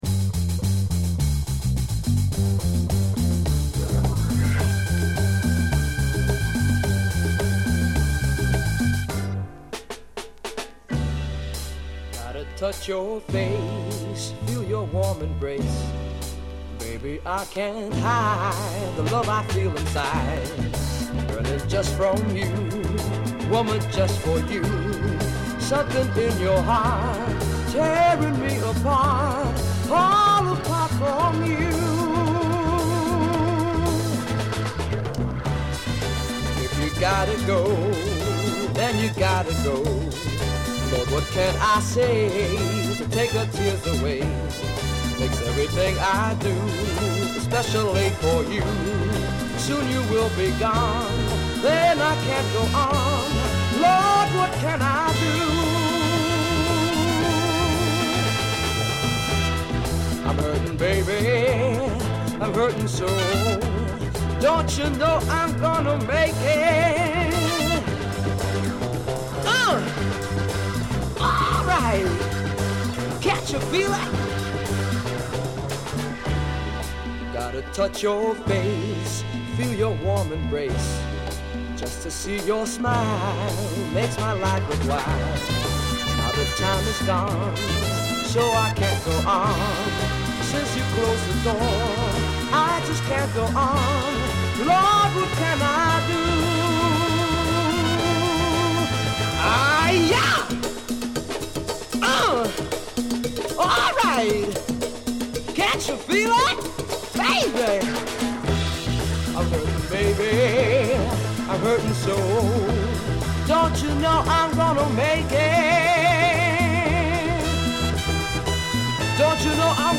straight up Latin heat